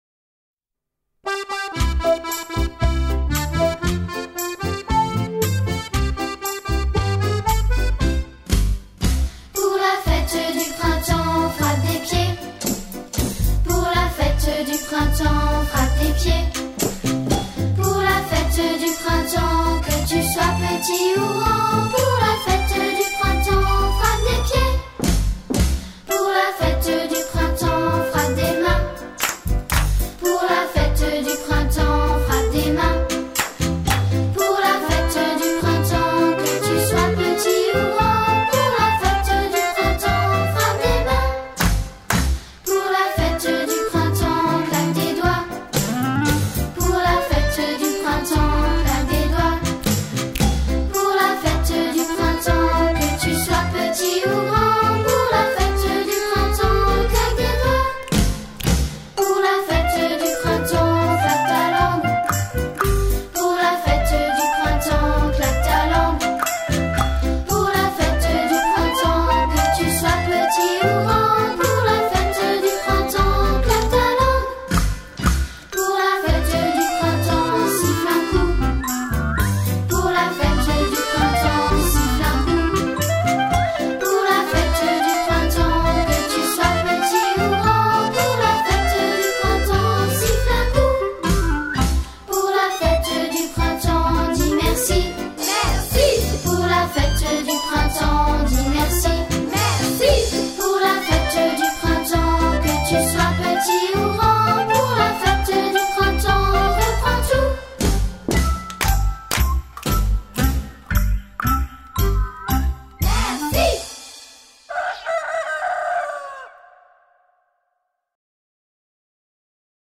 01 La Fête Du Printemps (vers. instr.).mp3